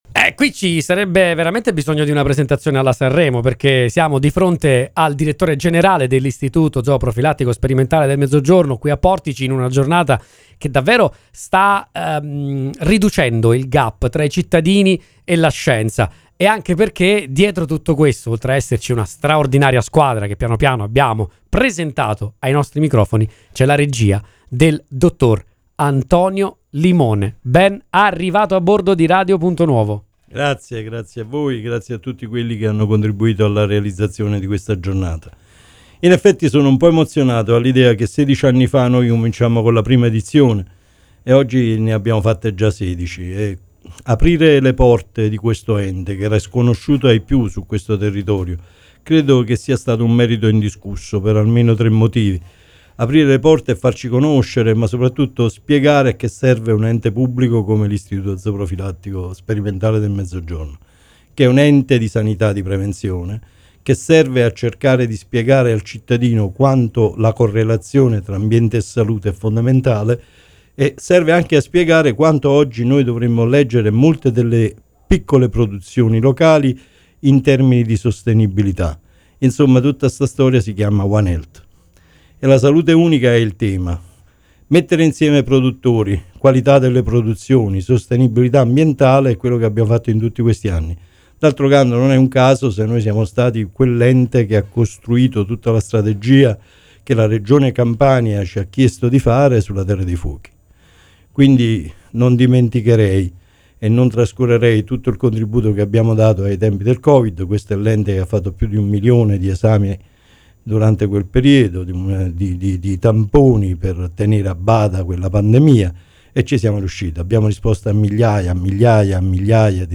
Fattorie Aperte, la sedicesima edizione è un grande successo
DI SEGUITO IL PODCAST DELLE INTERVISTE